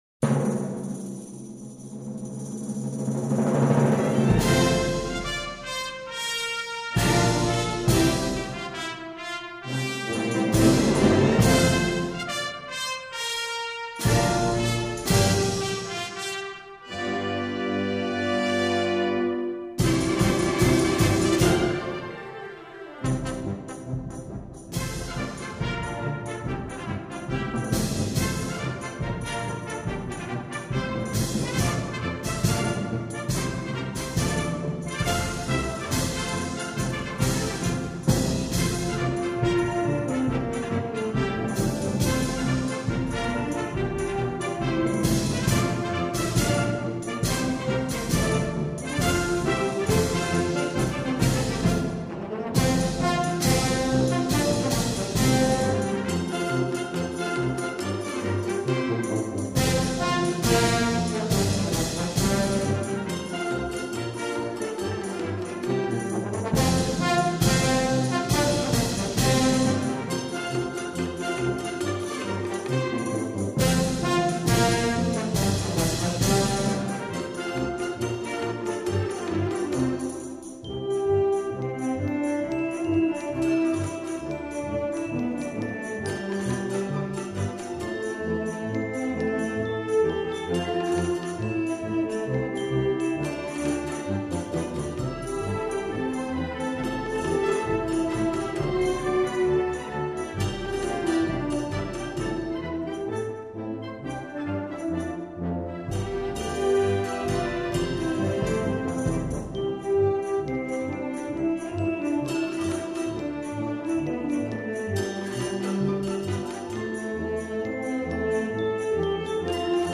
在风格和特色上，更以其演艺上乘，形式各样，格调清新，雅俗共赏，
寓教于乐，气势磅礴而独树一帜。
组合的合奏、重奏、齐奏；各种管乐器的独奏。